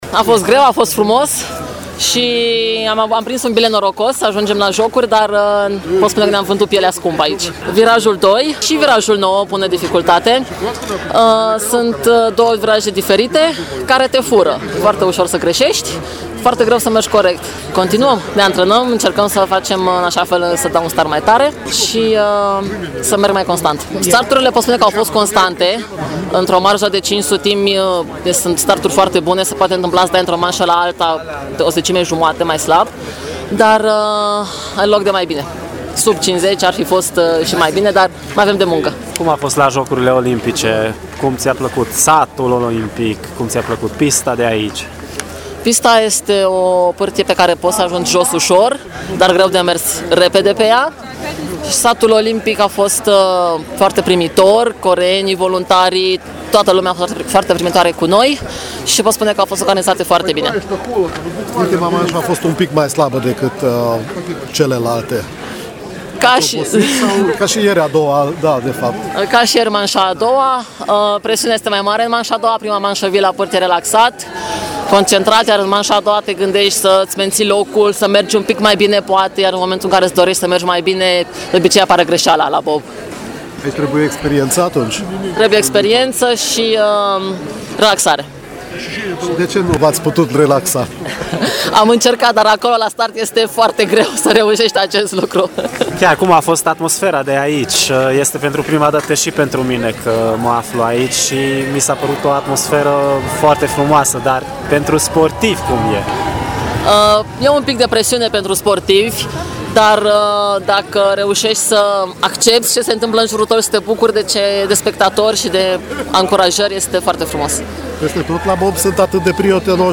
Cele două românce au încheiat cele 4 manșe cu timpul de 3 minute 25 de secunde și 53 de sutimi. Interviuri cu cele două reprezentante ale României